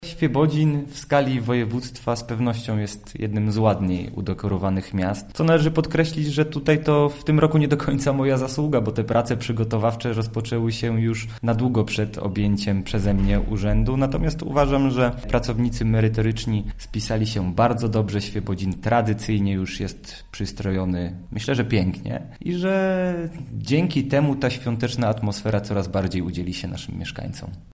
– Świebodzin zawsze się stara odświętnie wyglądać w ten szczególny czas – mówi Tomasz Sielicki, burmistrz Świebodzina.